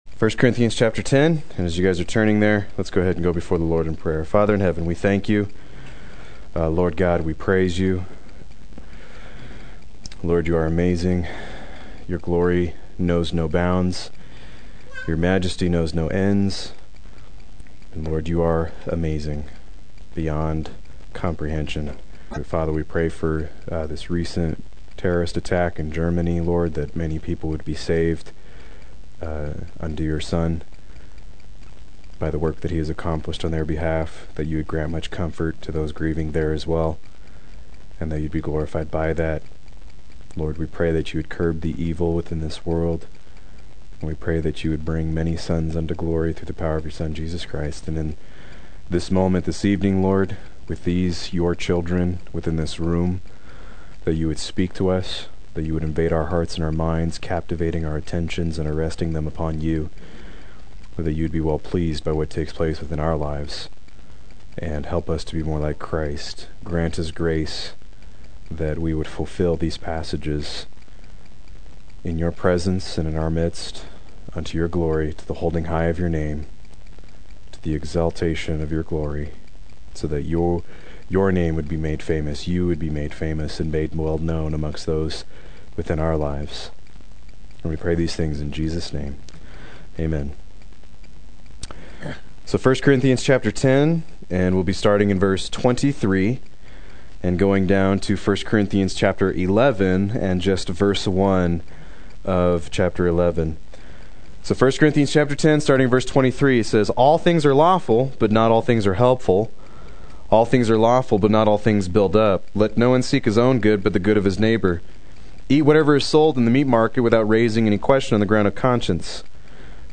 Proclaim Youth Ministry - 07/22/16
Play Sermon Get HCF Teaching Automatically.